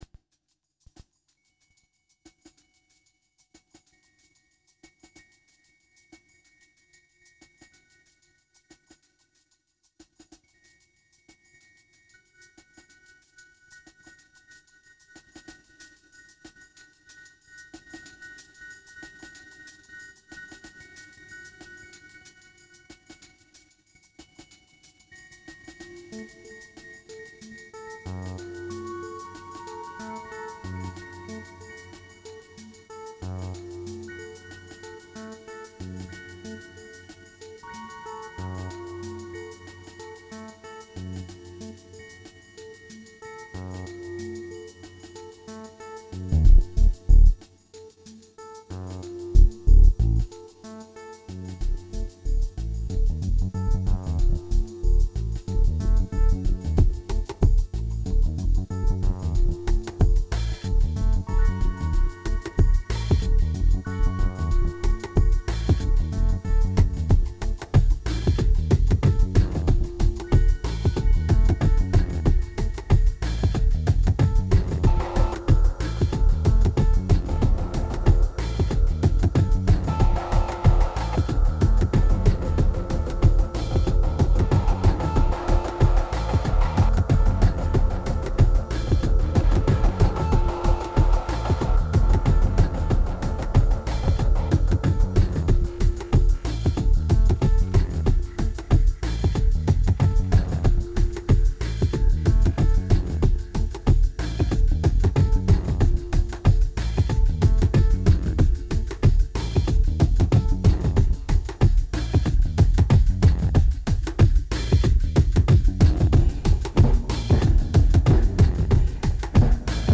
2254📈 - -2%🤔 - 93BPM🔊 - 2010-11-11📅 - -326🌟